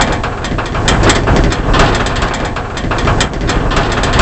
rr3-assets/files/.depot/audio/sfx/car_damage/impact_bodyflap_2.wav
impact_bodyflap_2.wav